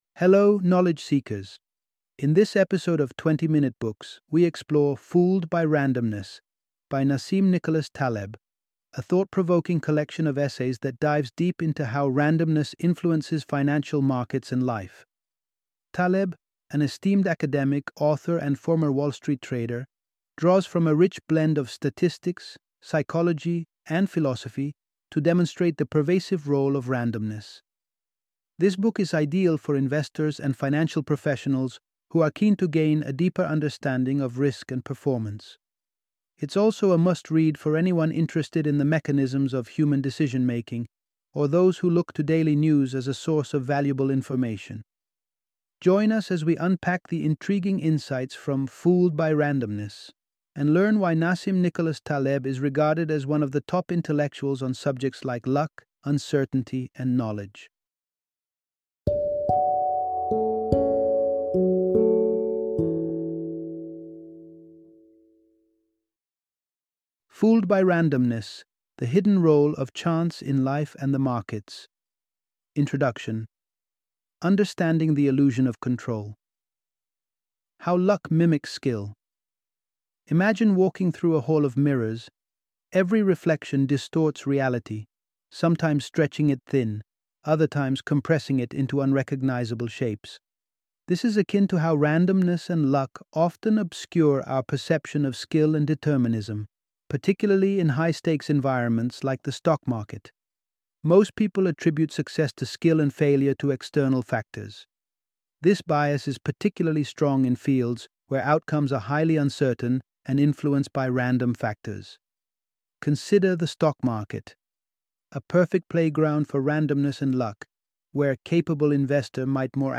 Fooled by Randomness - Audiobook Summary